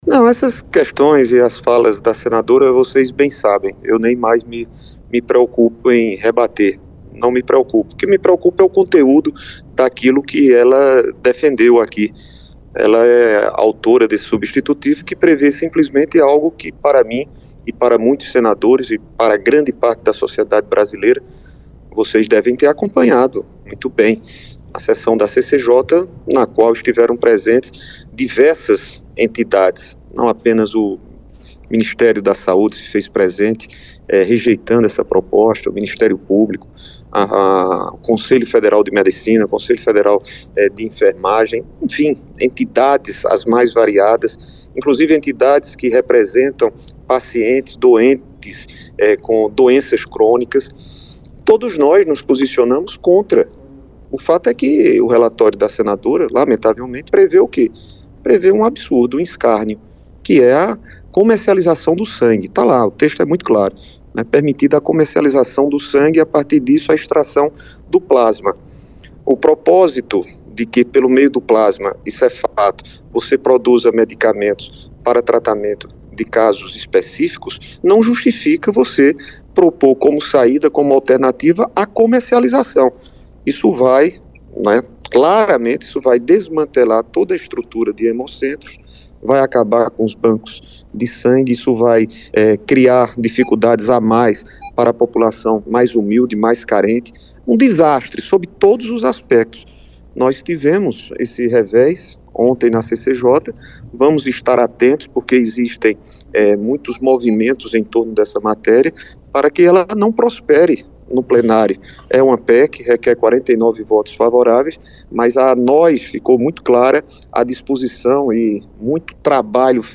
Declaração Veneziano